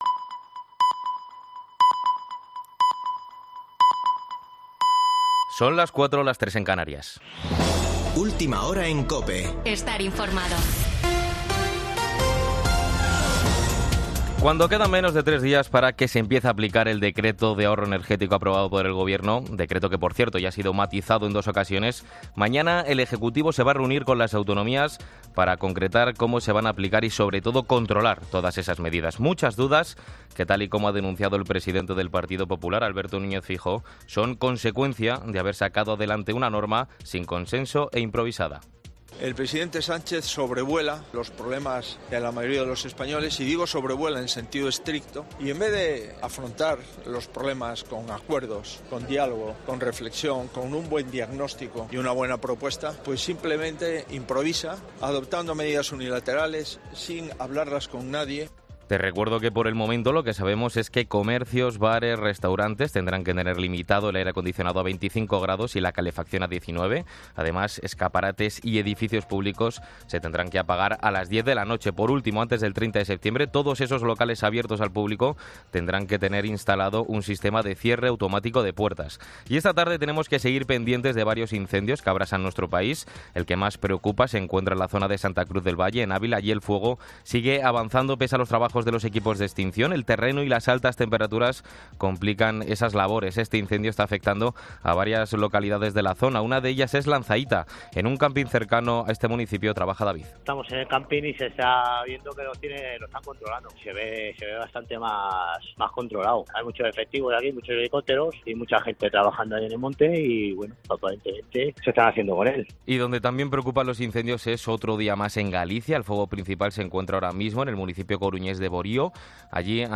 Boletín de noticias de COPE del 7 de agosto de 2022 a las 16.00 horas